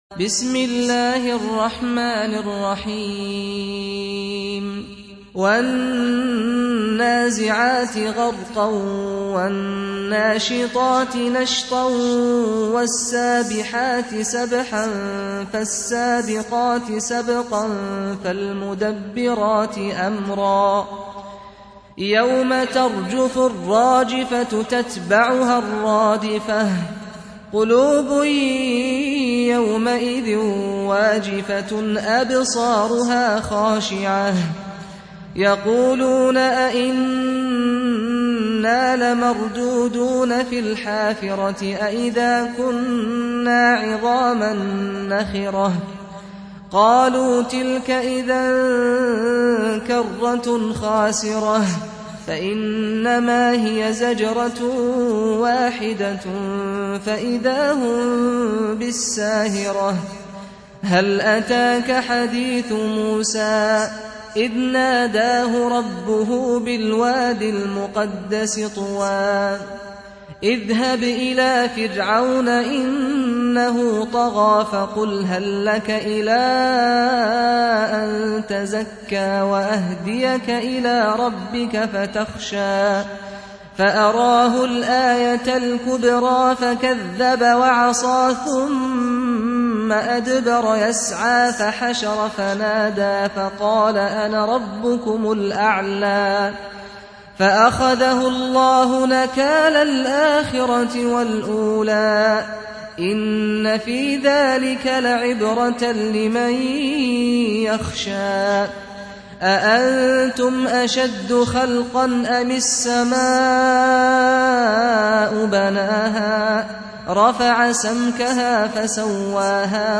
Surah Sequence تتابع السورة Download Surah حمّل السورة Reciting Murattalah Audio for 79. Surah An-Nazi'�t سورة النازعات N.B *Surah Includes Al-Basmalah Reciters Sequents تتابع التلاوات Reciters Repeats تكرار التلاوات